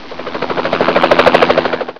1 channel
chopr.wav